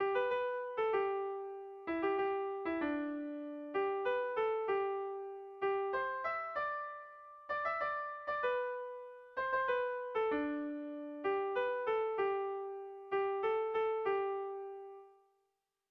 Kontakizunezkoa
Lauko handia (hg) / Bi puntuko handia (ip)
AB